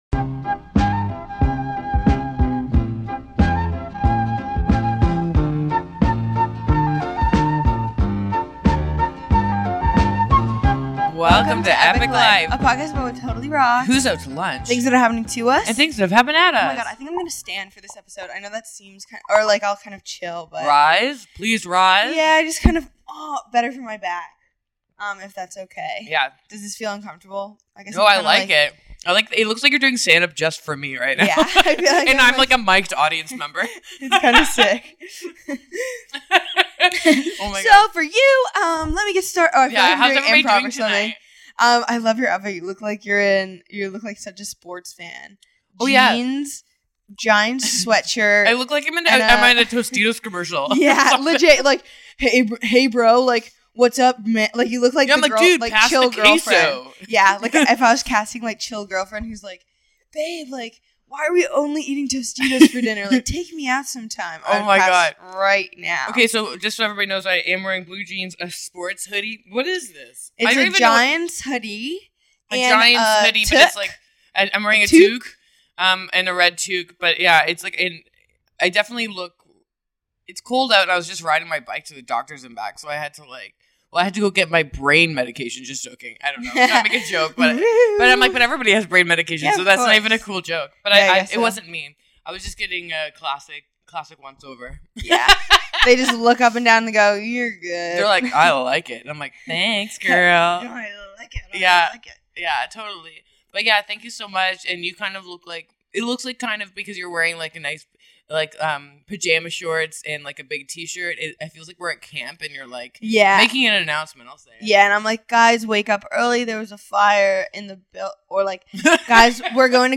Let's be honest the sound qual is an issue for sure but you're still into it!